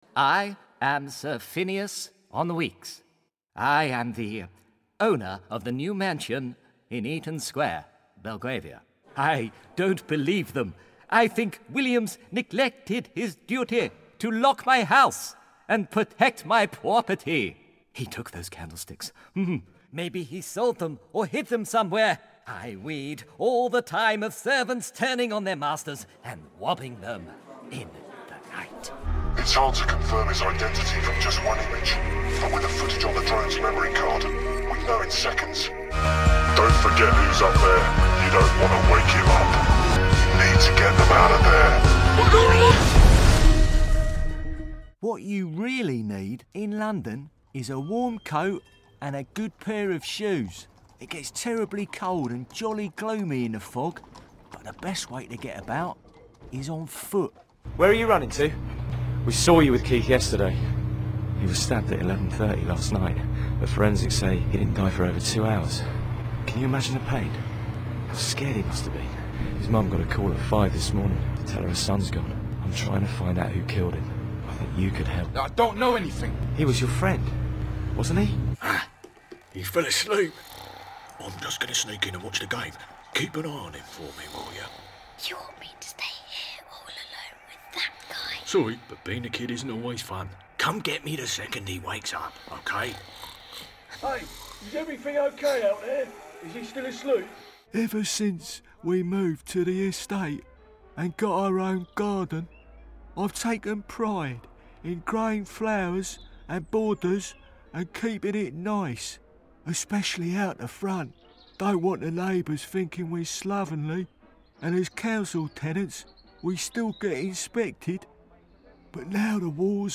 Male
English (British)
Adult (30-50)
He has an assured vocal delivery, with a clear, professional edge.
Character / Cartoon
All our voice actors have professional broadcast quality recording studios.